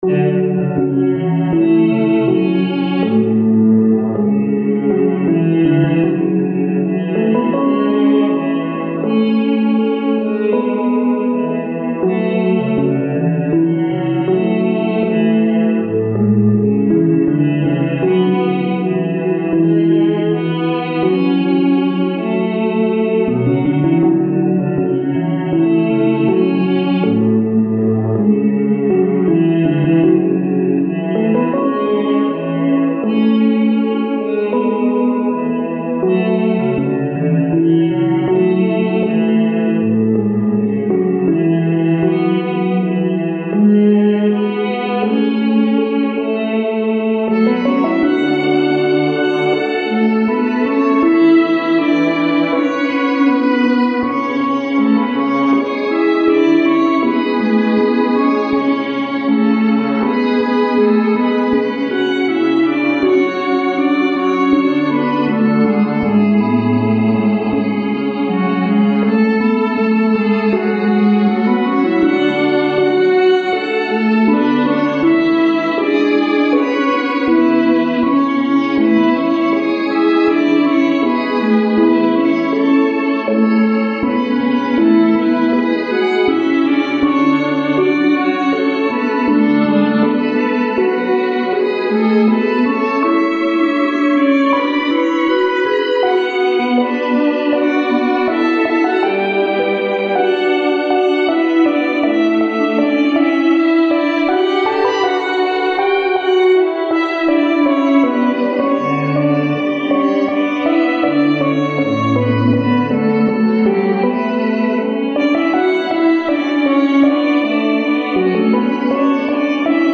それぞれ１ループの音源です♪
イントロなし